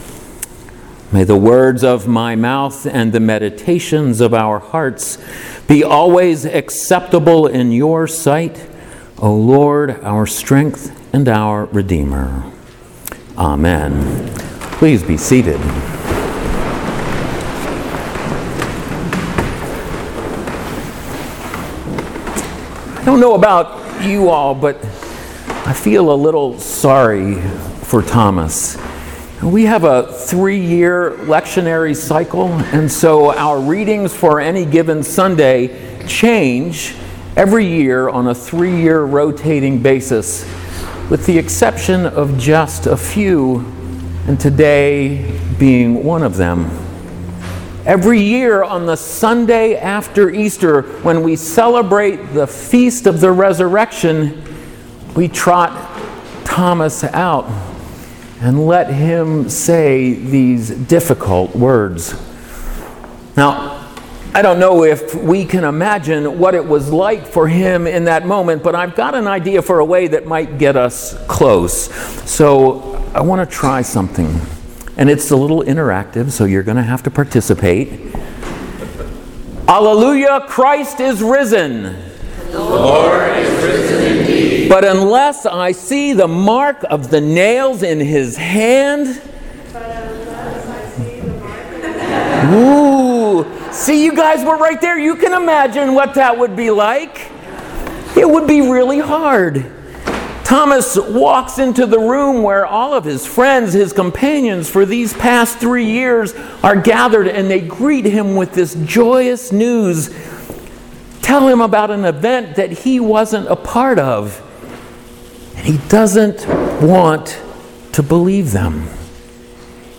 sermon-easter-2a-2017.mp3